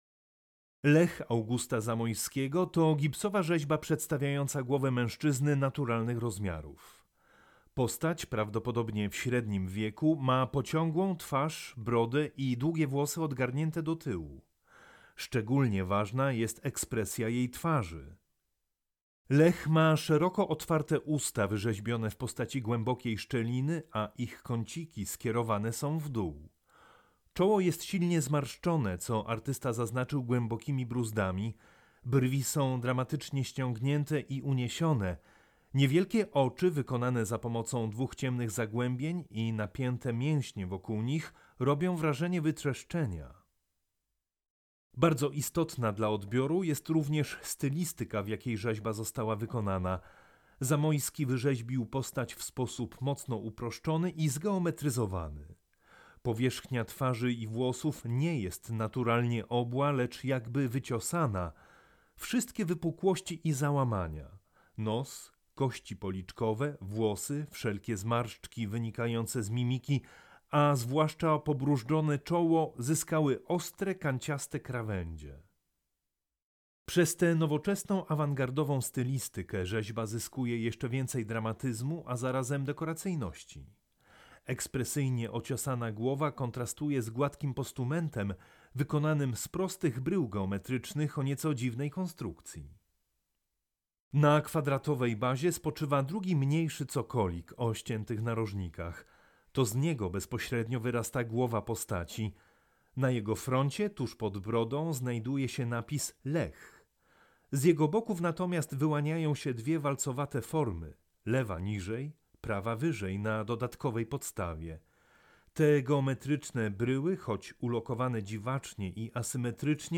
AUDIODESKRYPCJA
AUDIODESKRYPCJA-August-Zamoyski-Lech.mp3